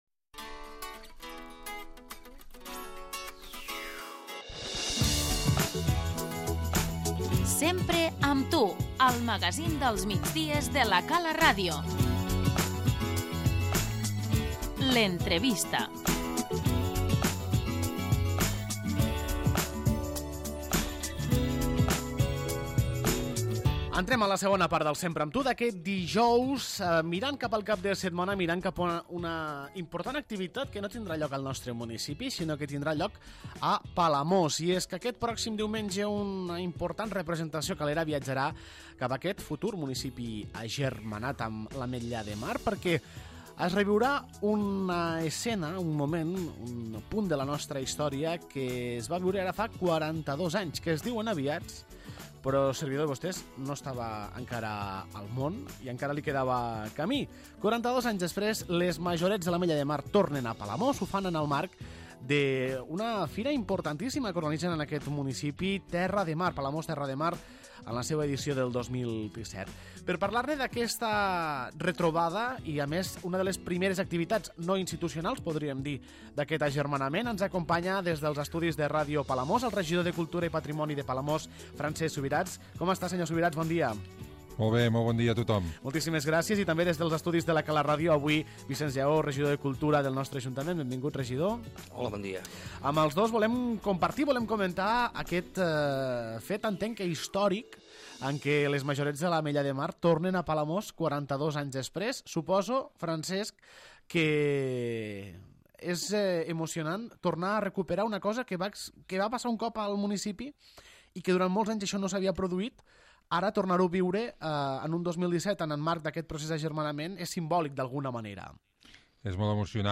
L'entrevista - Vicenç Llaó i Francesc Subirats, regidors de Cultura de l'Ametlla de Mar i Palamós
Les Majorettes de l'Ametlla de Mar, acompanyades per la Banda de Cornetes i Tambors, tornaran a desfilar aquest diumenge pels carrers de Palamós, 42 anys després. Analitzem aquest fet històric amb els regidors de Cultura d'ambdós municipis, Vicenç Llaó i Francesc Subirats.